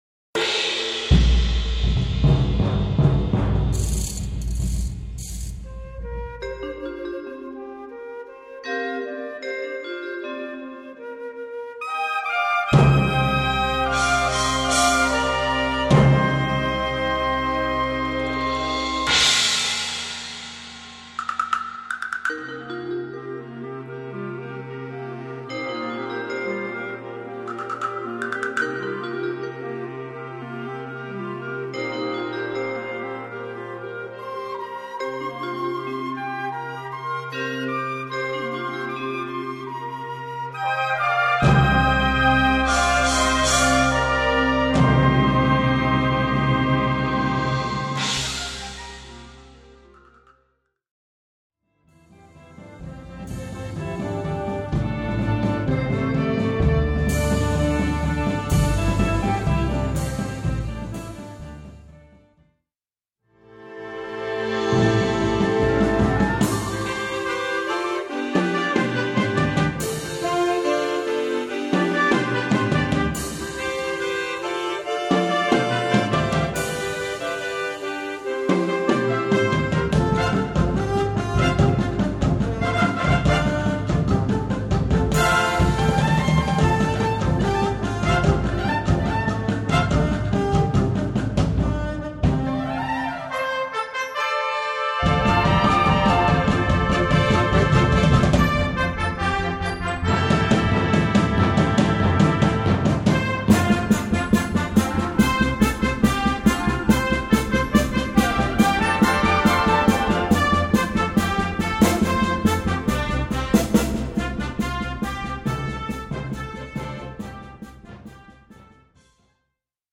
Gattung: Polka
5:10 Minuten Besetzung: Blasorchester PDF